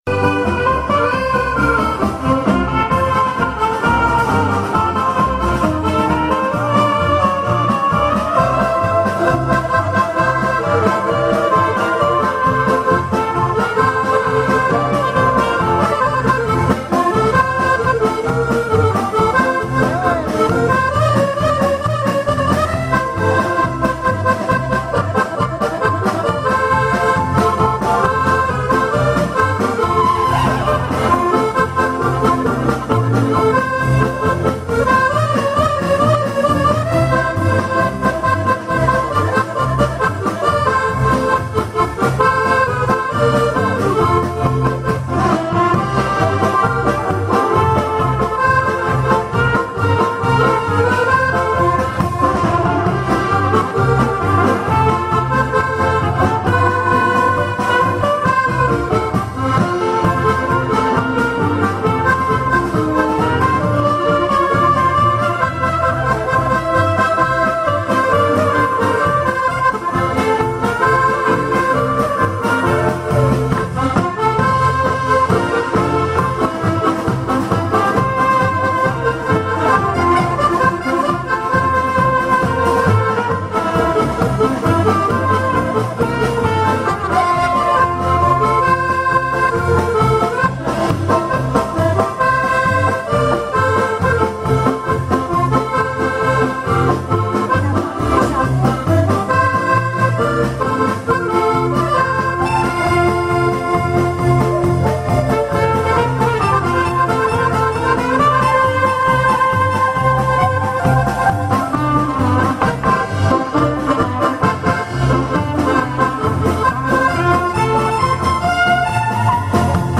Bal-musette